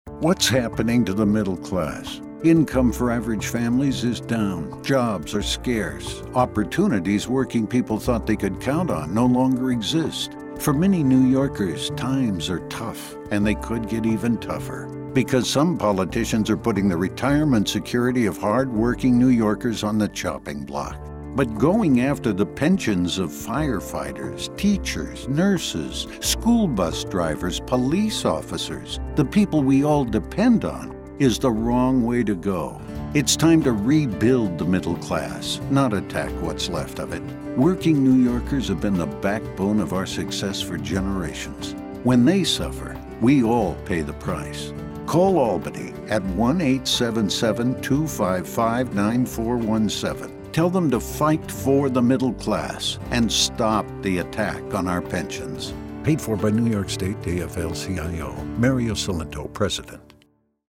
AFL-CIO Radio Spot Opposing Tier 6